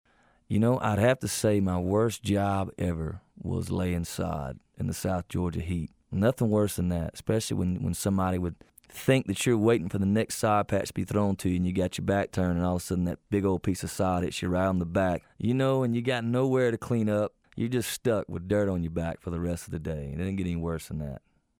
Audio / Kip Moore recalls his worst job...ever.